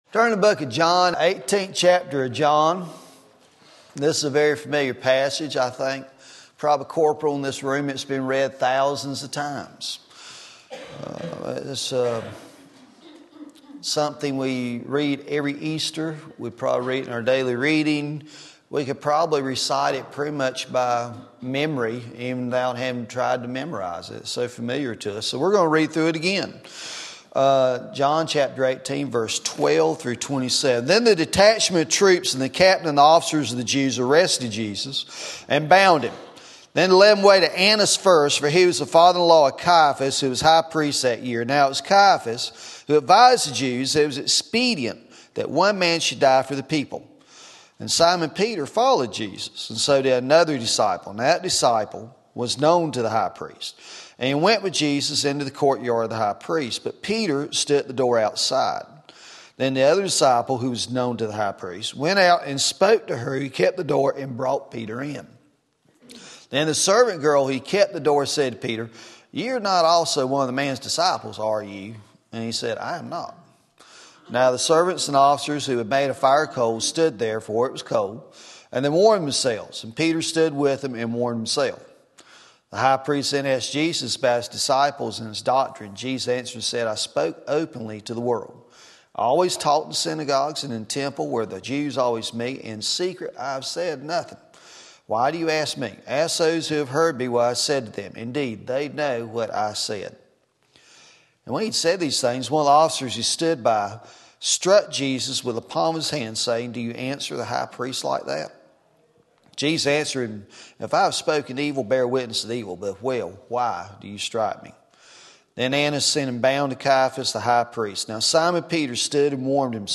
Sermon Link